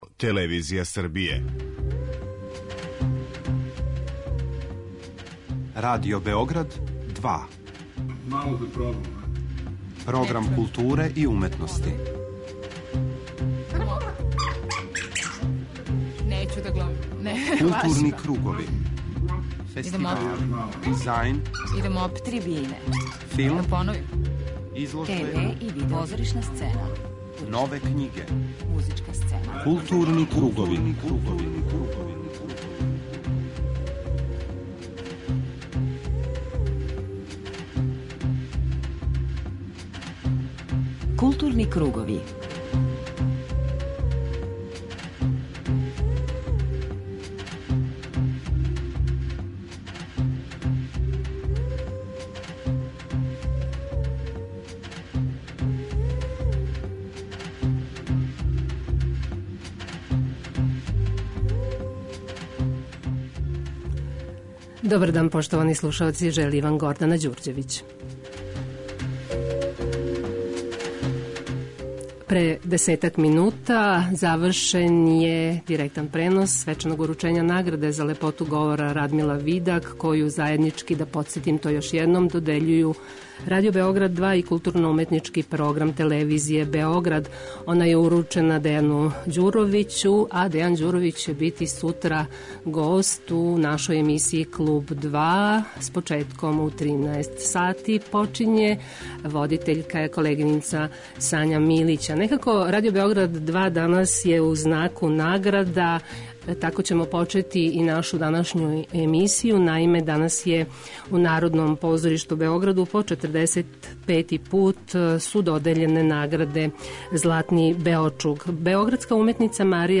Славни руски драмски писац Николај Кољада био је гост првог Београдског фестивала савремене руске драме, који је ових дана одржан у Установи културе "Вук Караџић", као и Београдског драмског позоришта, где су почеле пробе представе по његовом комаду "Кључеви од Лераха".